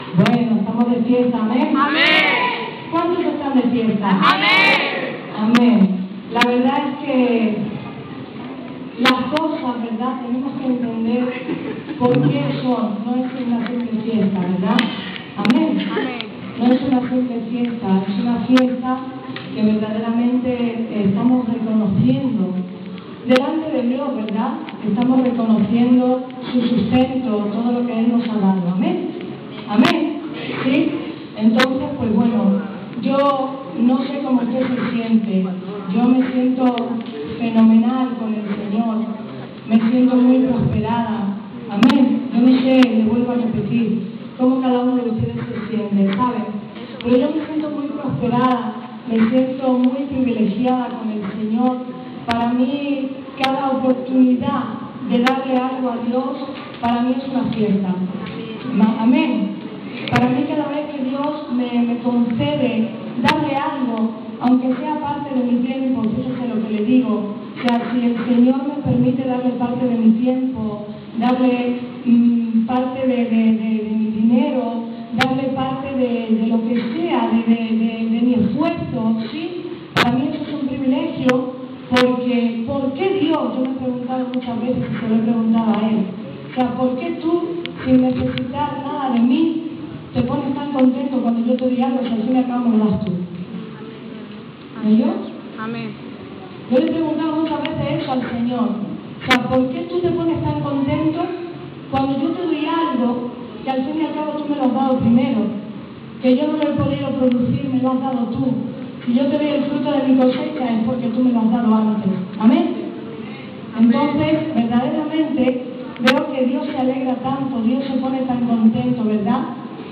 Sermó religiós des de La Igleisa Evangélica La Paz
Religió